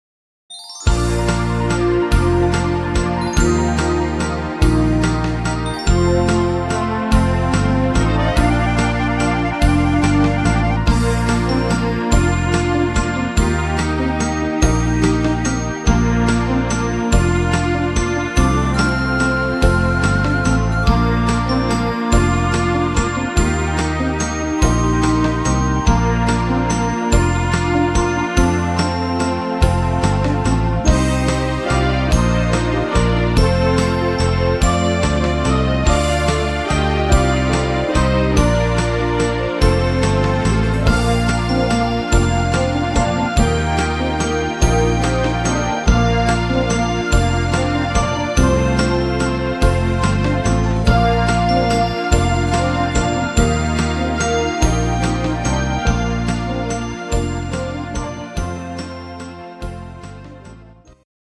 Schunkel-Walzer Version